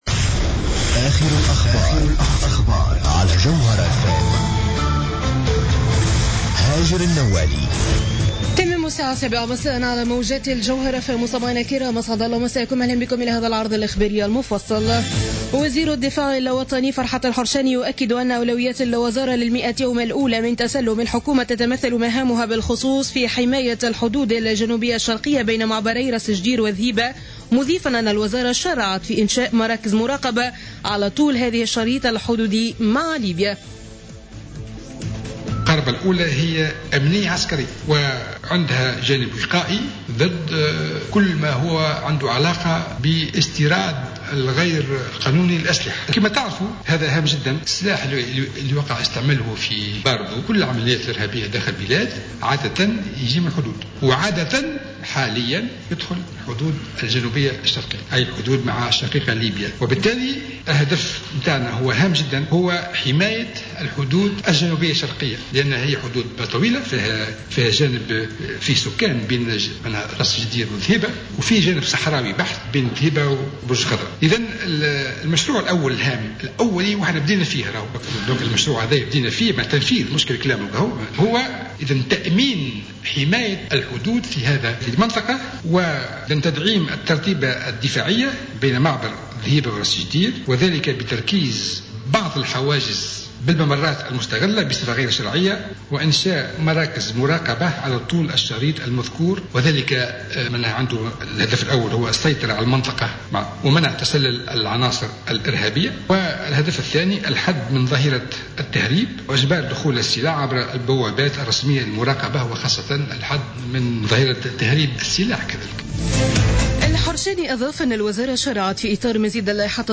نشرة أخبار السابعة مساء ليوم الخميس 16 أفريل 2015